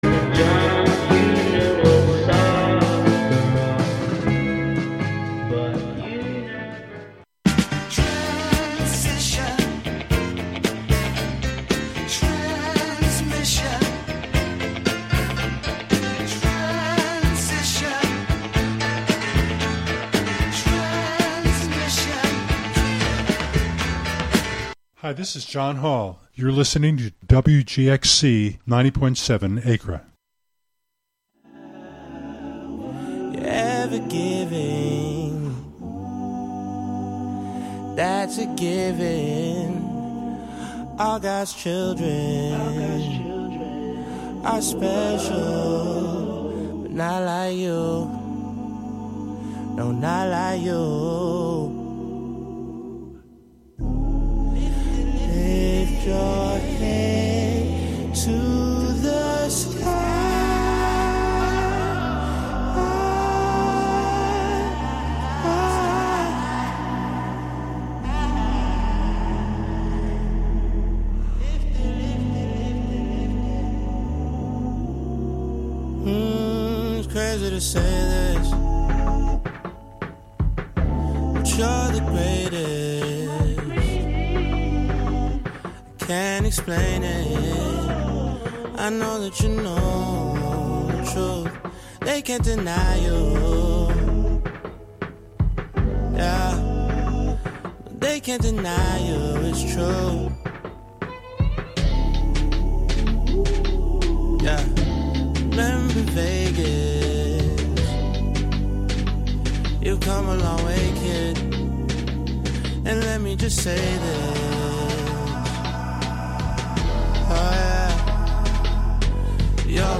Through Censored, The Word Shop, and Our Town Our Truth, we dig into the topics that matter. Our container: Radiolab, an open, experimental, youth-led programming and recording space. Get yr weekly dose of music appreciation, wordsmithing, and community journalism filtered through the minds and voices of the Youth Clubhouses of Columbia-Greene, broadcasting live out of the Catskill Clubhouse on Fridays at 6 p.m. and rebroadcast Sundays at 7 a.m.